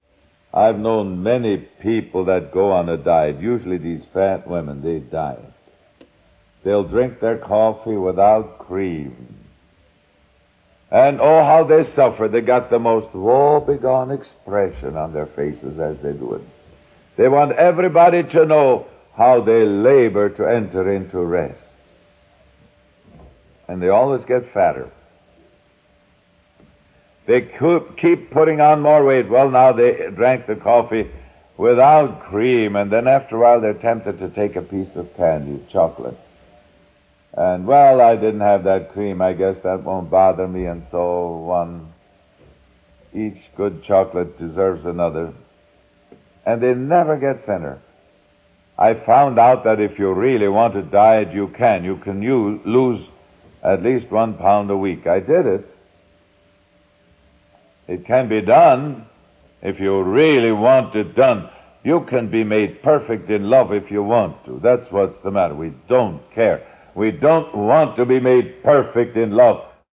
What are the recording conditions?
Audio Quality: Mixed We tried to further reduce hiss by a second pass of hiss reduction, but there was too much degradation in the rest of the spectrum to allow this.